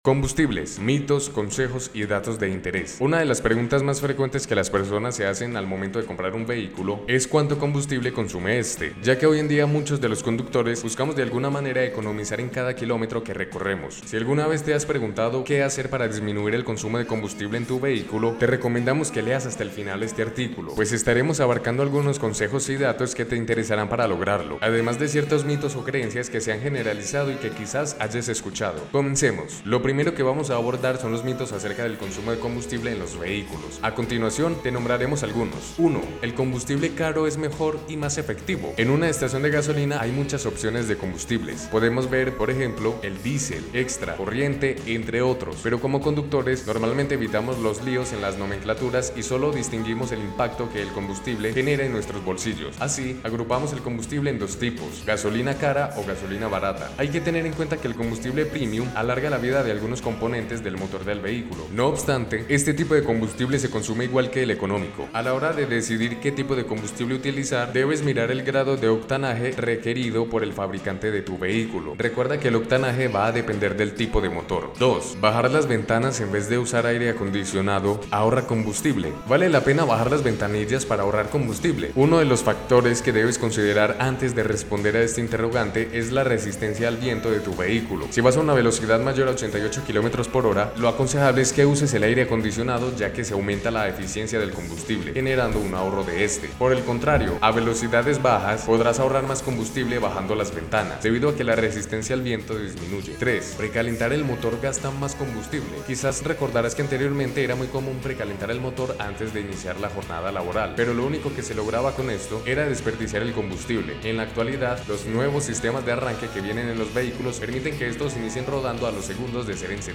No te preocupés, acá te lo leemos.